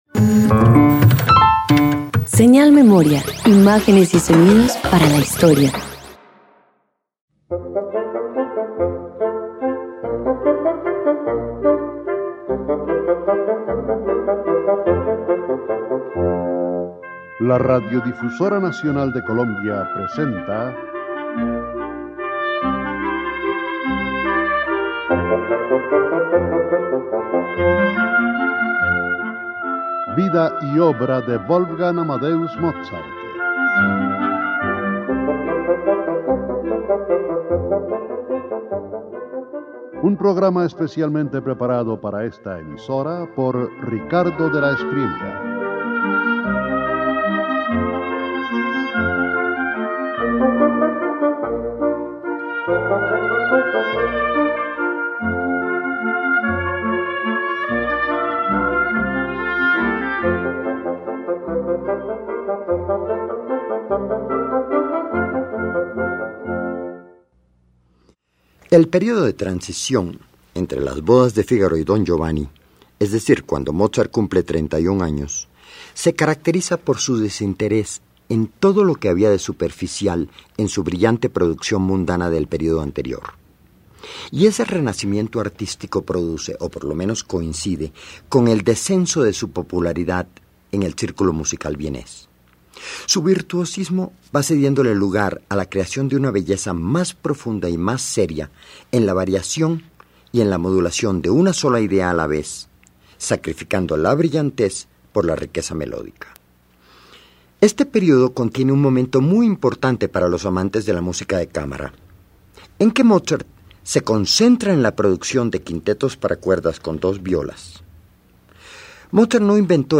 260 Quintetos para cuerdas Parte I_1.mp3